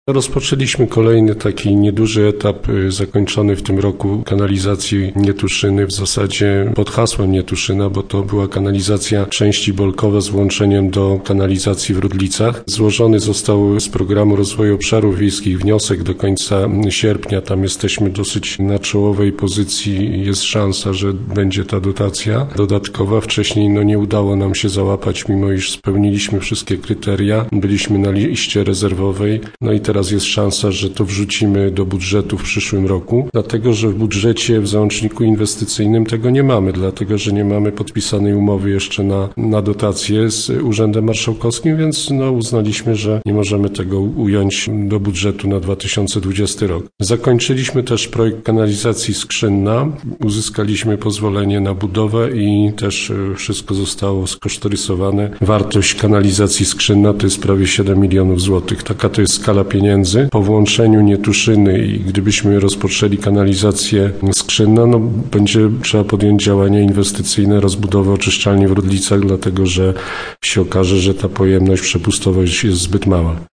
O tym, jak ważne i kosztowne są to zadania, mówił na naszej antenie wójt, Ryszard Turek: Rozpoczęliśmy kolejny, nieduży etap, zakończony w tym roku, kanalizacji Nietuszyny, w zasadzie pod hasłem Nietuszyna, bo była to kanalizacja części Bolkowa z włączeniem do kanalizacji w Rudlicach.